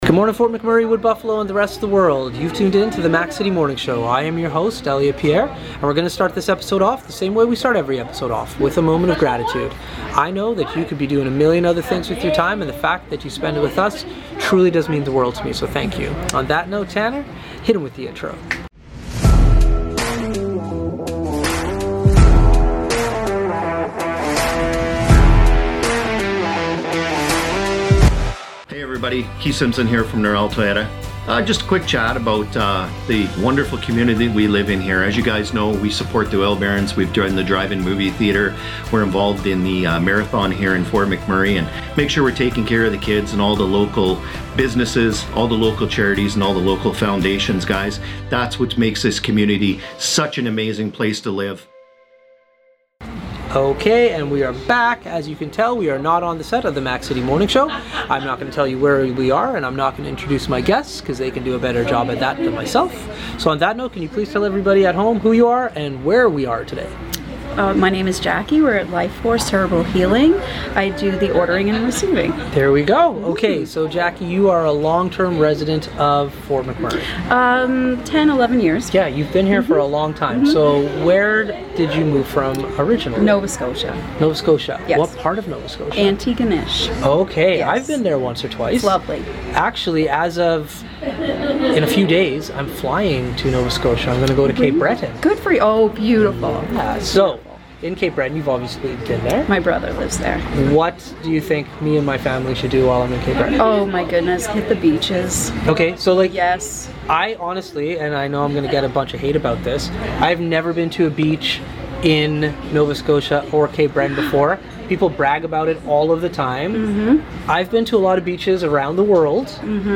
We are on location